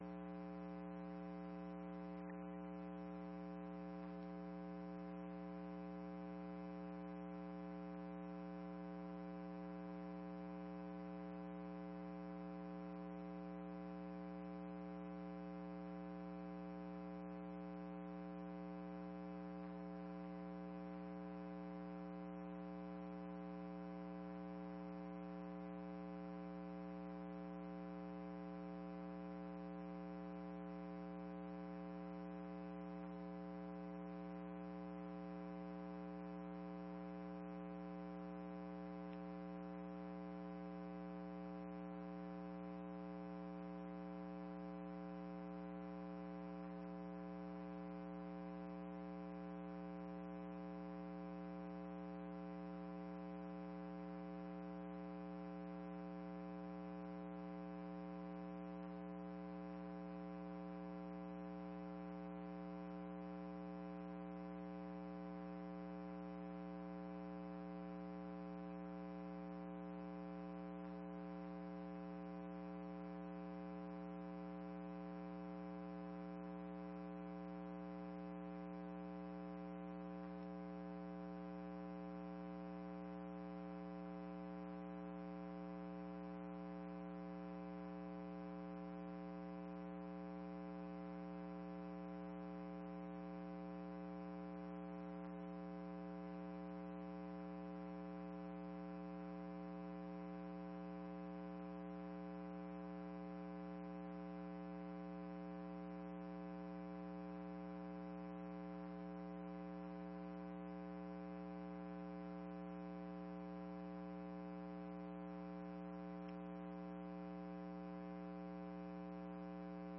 6ª Audiência Publica com o tema regulamentação som automotivo Anápolis da 4ª Sessão Legislativa da 19ª Legislatura Dia 10/06/2024